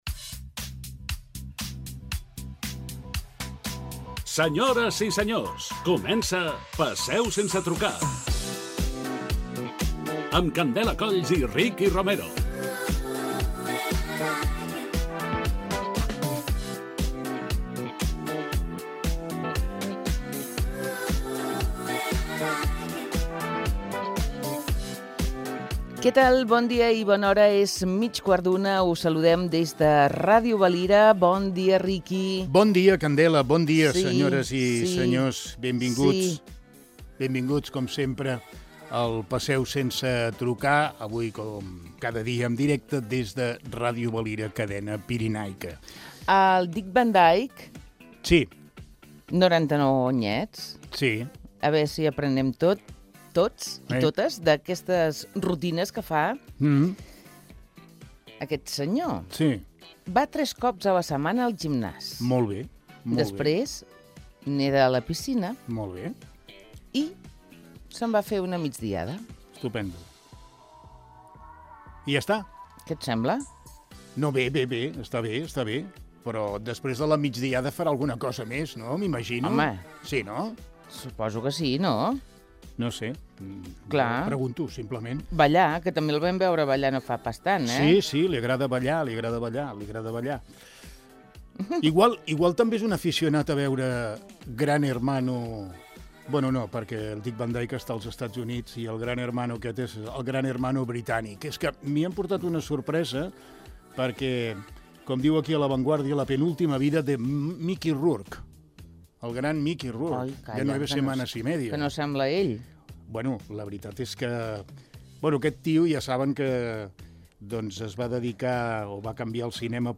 PST Nº 2.741 ENTREVISTA